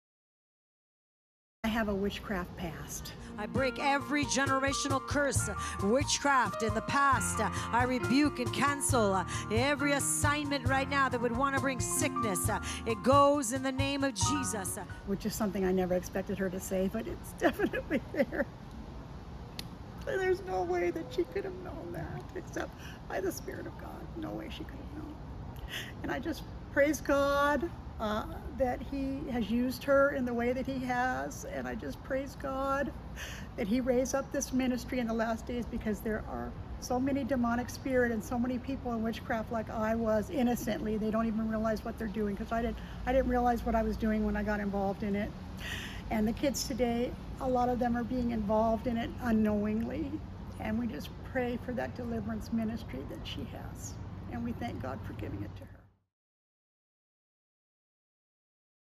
overwhelmed to tears